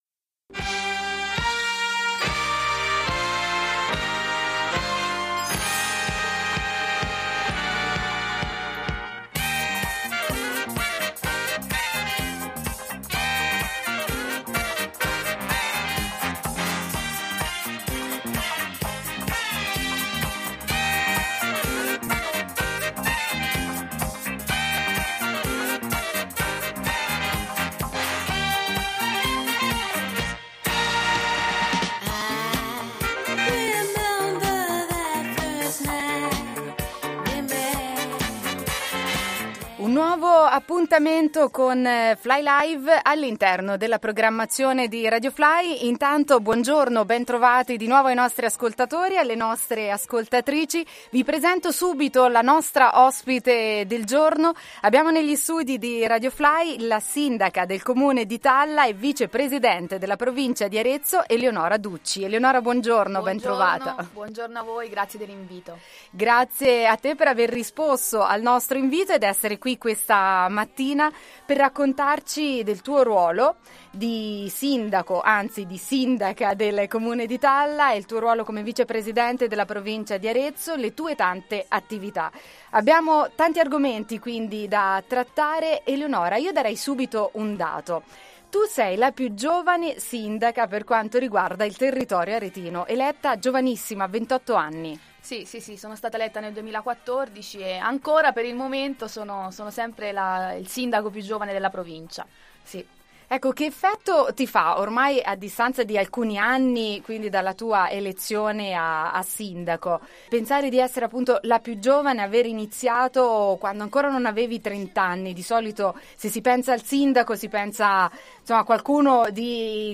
Intervista alla vicepresidente della Provincia di Arezzo e Sindaca di Talla Eleonora Ducci